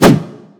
Punch Swing_F_0.wav